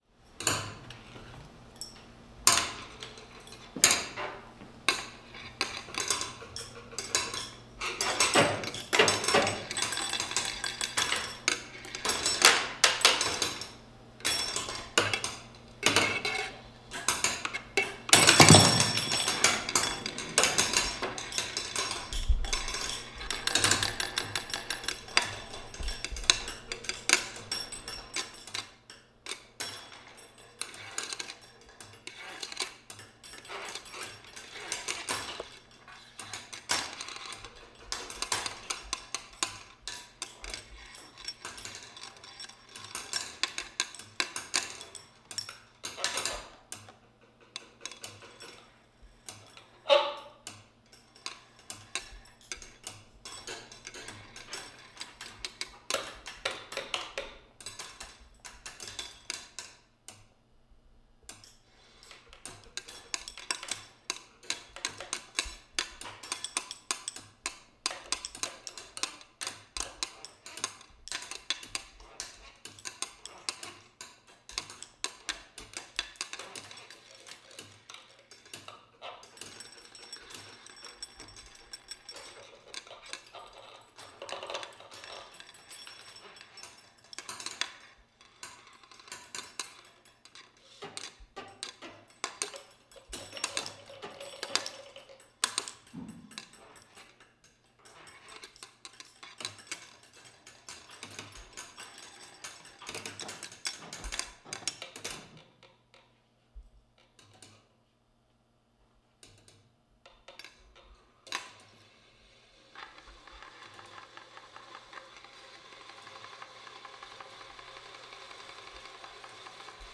(performance recording)
Instrumentation: turntable orchestra